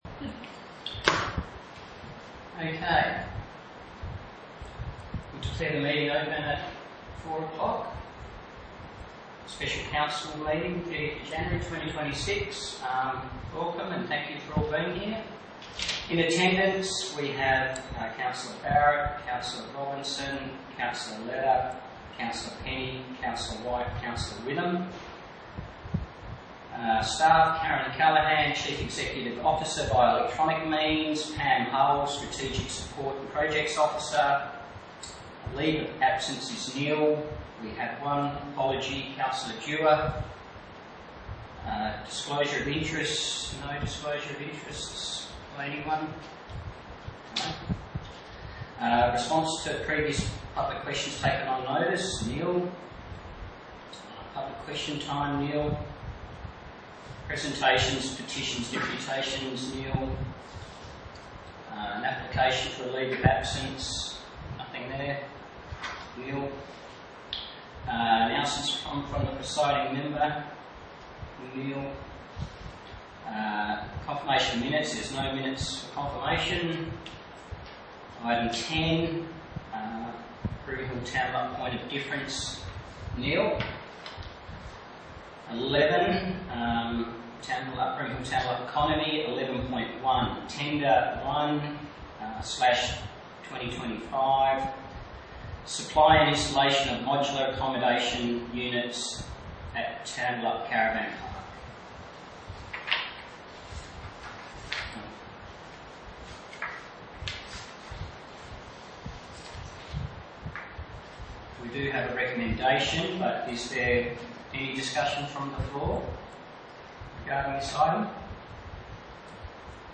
A Special Council Meeting will be held on Friday 30 January 2026 commencing at 4.00pm, in the Council Chambers, 46-48 Norrish St Tambellup.